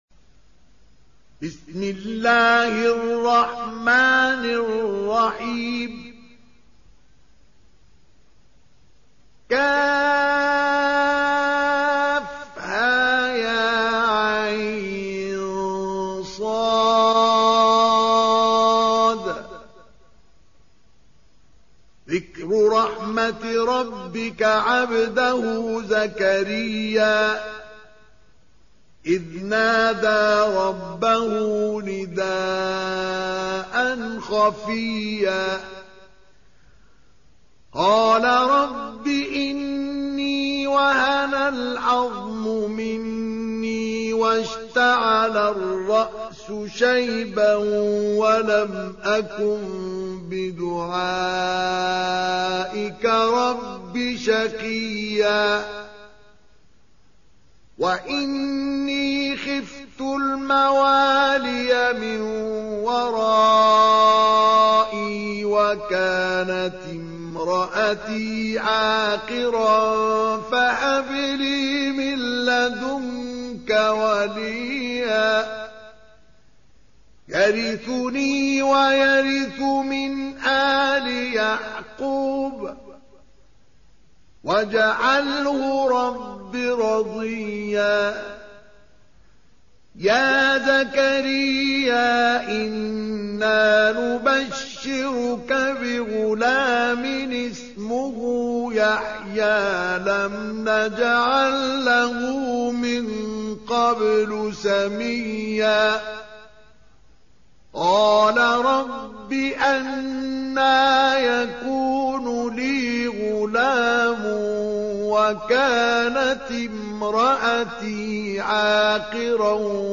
19. Surah Maryam سورة مريم Audio Quran Tarteel Recitation Home Of Sheikh Mustafa Ismail
Surah Sequence تتابع السورة Download Surah حمّل السورة Reciting Murattalah Audio for 19. Surah Maryam سورة مريم N.B *Surah Includes Al-Basmalah Reciters Sequents تتابع التلاوات Reciters Repeats تكرار التلاوات